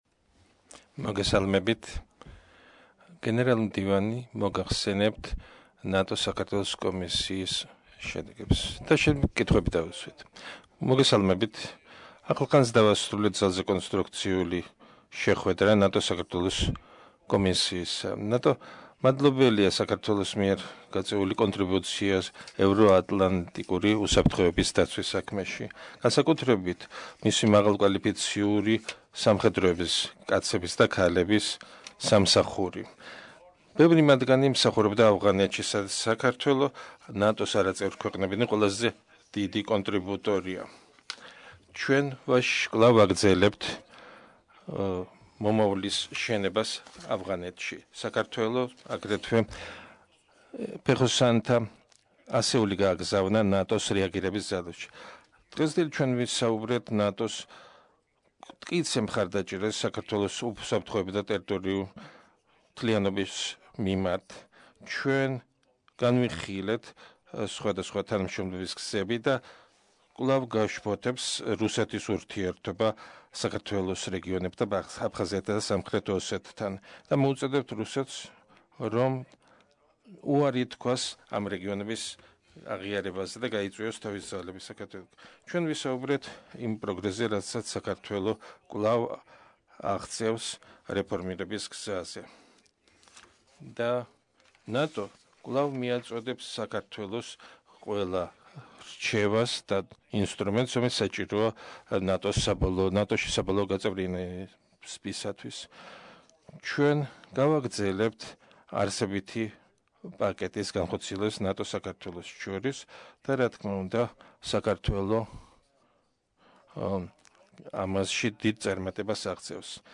Press conference by NATO Secretary General Jens Stoltenberg following the meeting of the NATO-Georgia Commission at the level of NATO Foreign Ministers